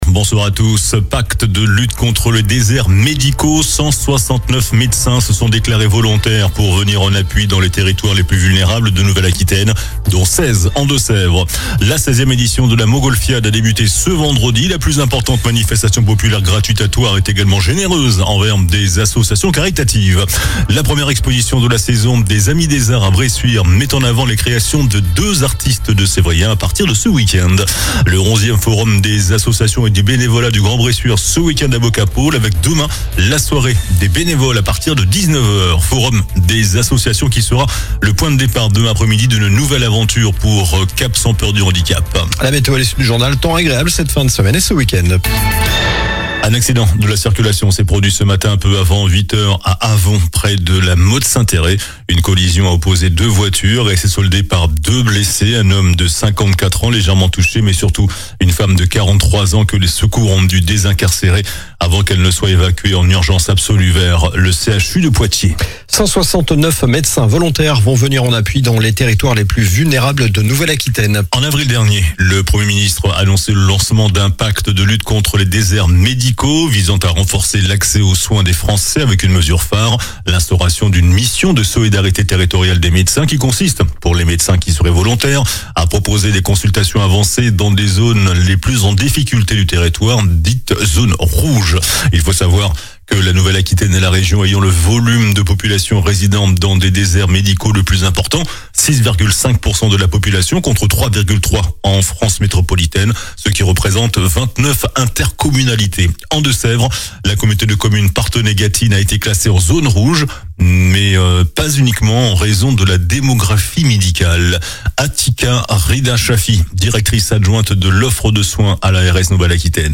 JOURNAL DU VENDREDI 05 SEPTEMBRE ( SOIR )